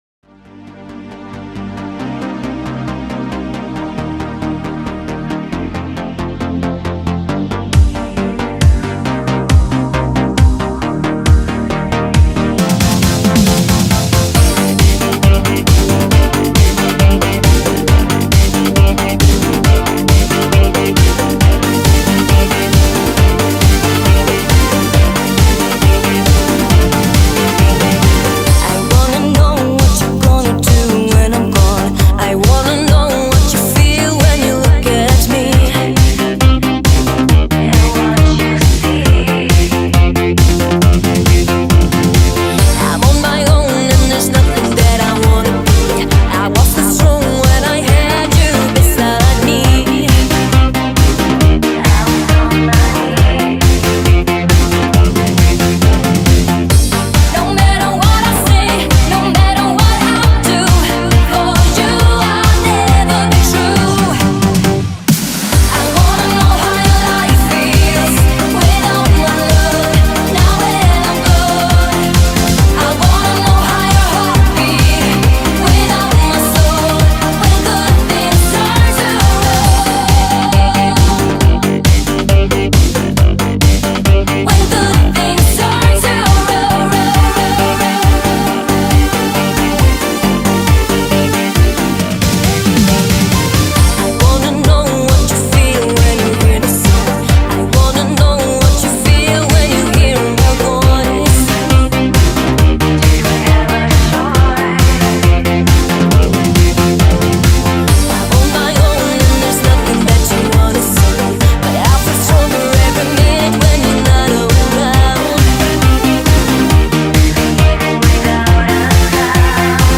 И такой переход к хорошему диско))